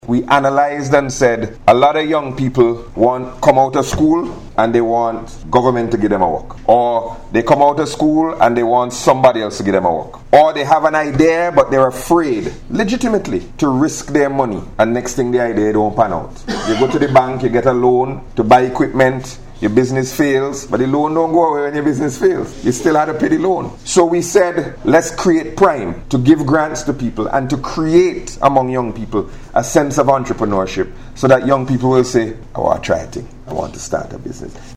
Speaking at a recent event, Minister Gonsalves noted that many school leavers with promising ideas often fear financial risk, which limits their potential.